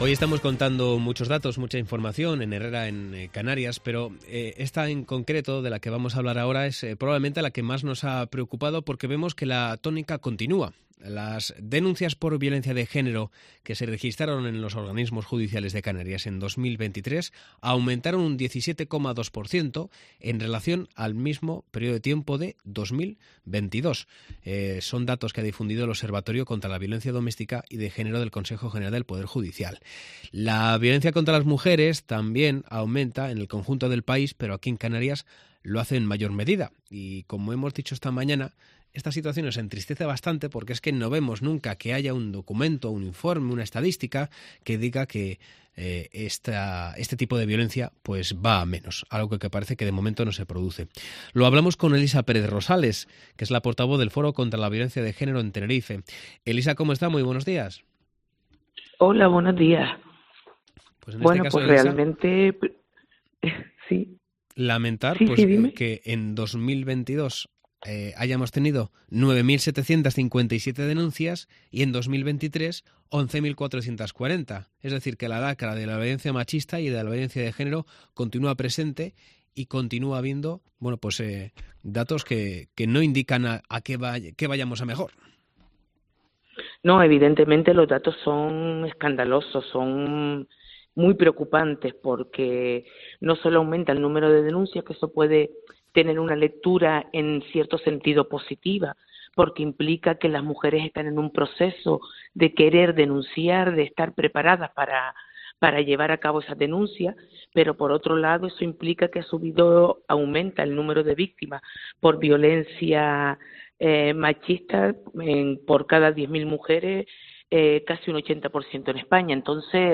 Durante la emisión de COPE Canarias